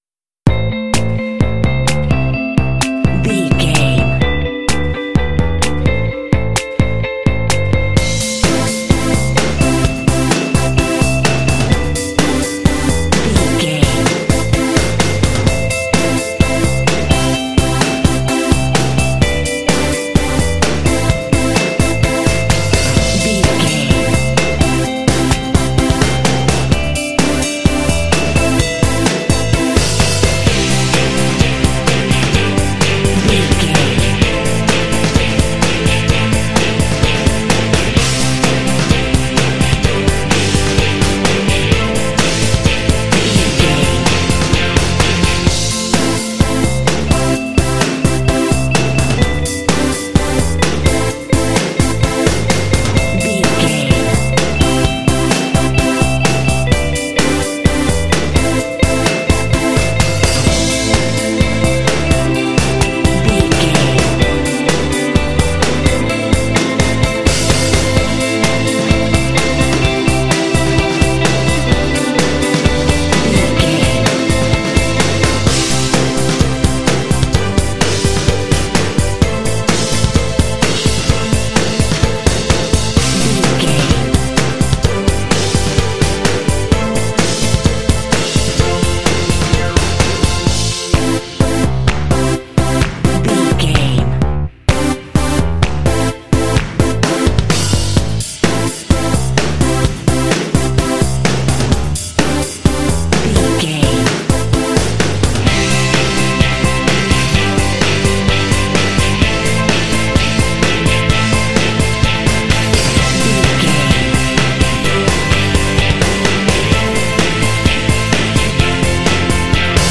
This track is ideal for rhythm and casual games.
Aeolian/Minor
fun
cool
calm
driving
strings
bass guitar
synthesiser
piano
drums
rock
alternative rock
indie